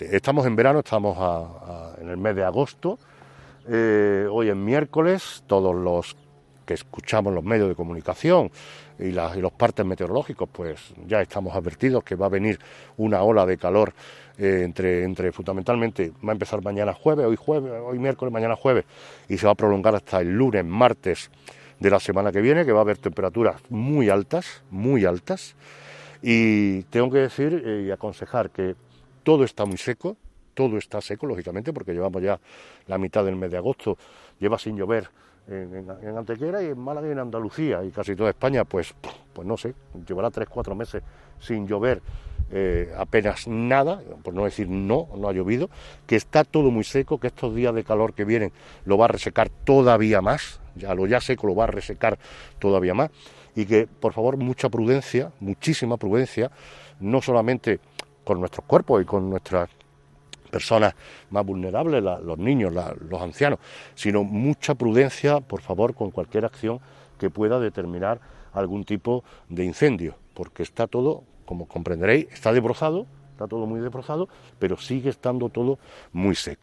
El alcalde de Antequera, Manolo Barón, y la teniente de alcalde delegada de Mantenimiento, Teresa Molina, han informado hoy en rueda de prensa sobre el desarrollo del Plan Municipal de Desbroce 2021 que ya se encuentra en fase de mantenimiento tras su acometida desde el pasado mes de marzo.
Cortes de voz